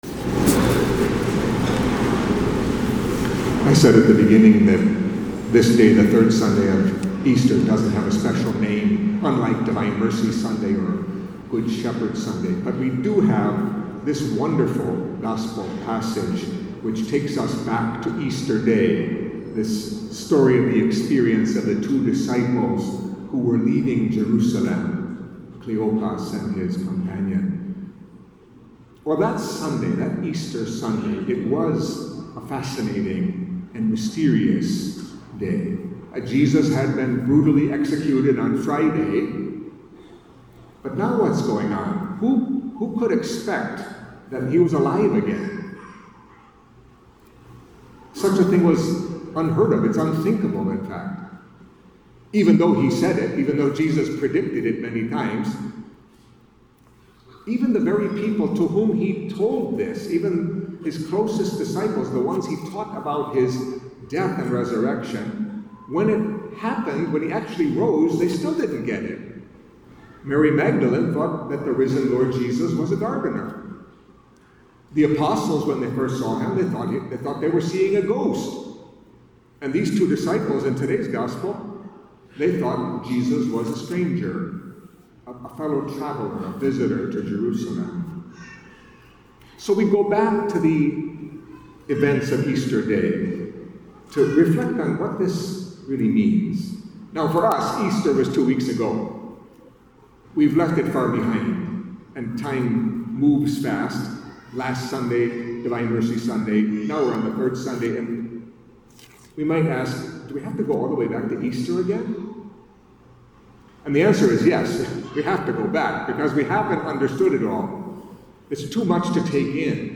Catholic Mass homily for Third Sunday of Easter